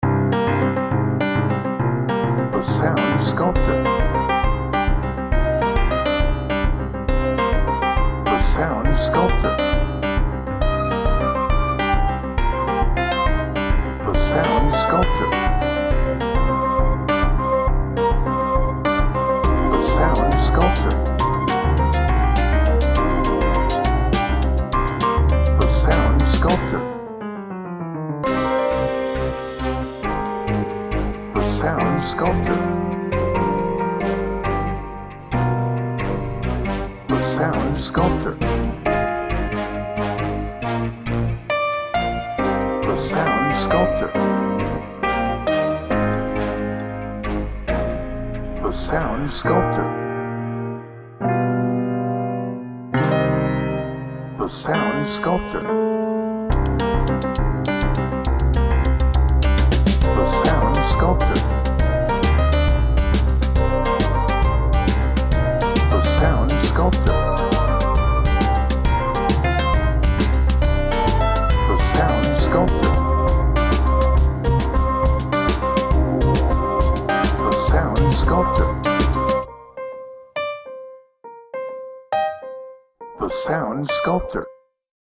Edgy
Jazz
Positive